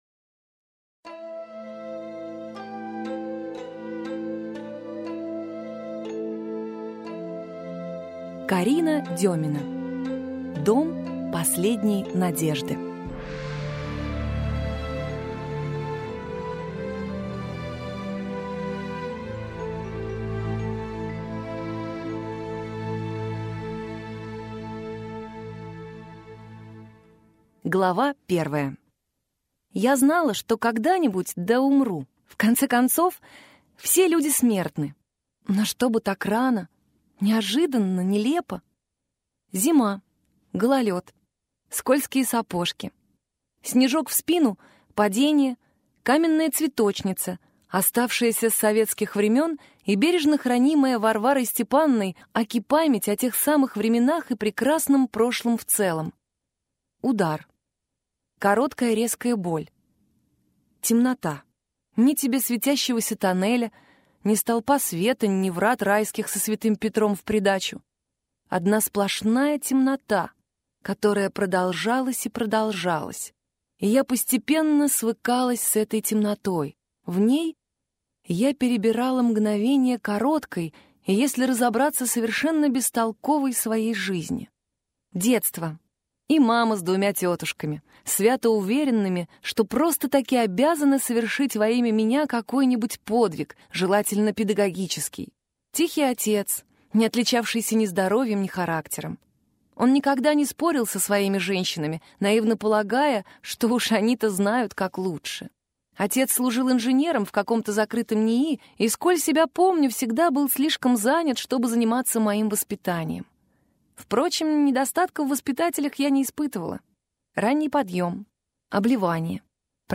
Аудиокнига «Дом последней надежды» в интернет-магазине КнигоПоиск ✅ в аудиоформате ✅ Скачать Дом последней надежды в mp3 или слушать онлайн